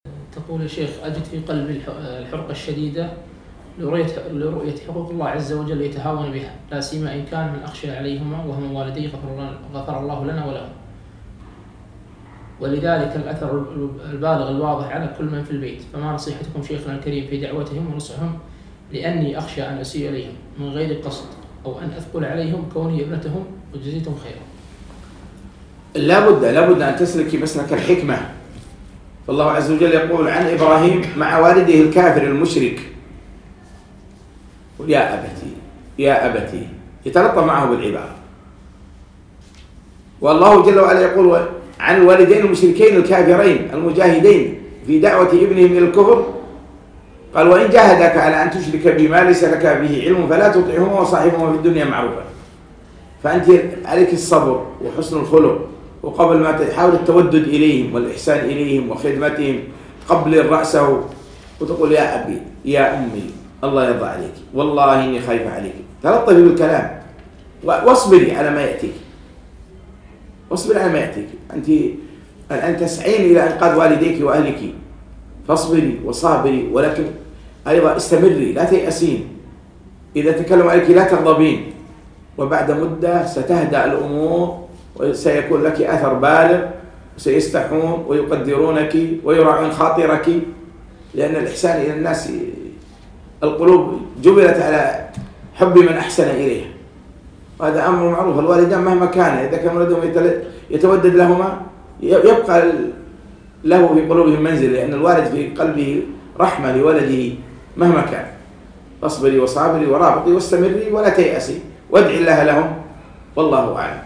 مقتطف من محاضرة فوائد وعبر من سورة الكهف المقامة في مركز إلهام البوشي بتاريخ 3 4 2017